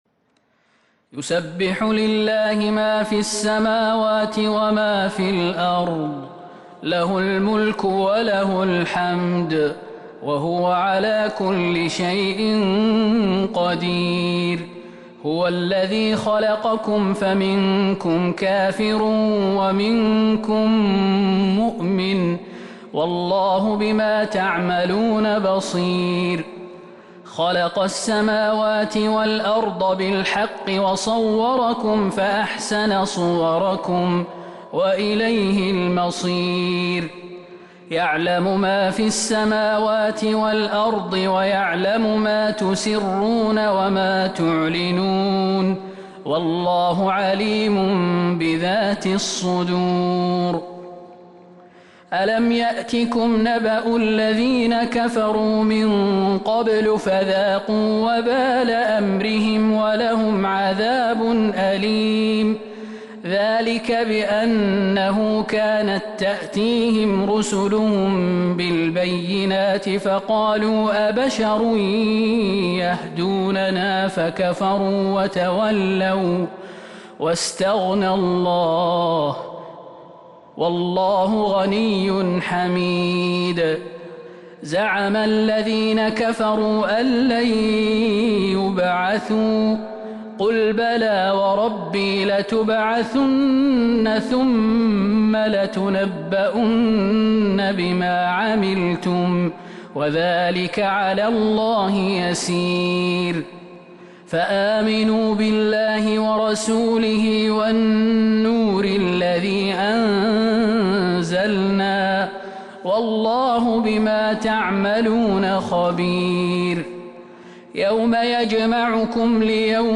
سورة التغابن Surat At-Taghabun من تراويح المسجد النبوي 1442هـ > مصحف تراويح الحرم النبوي عام 1442هـ > المصحف - تلاوات الحرمين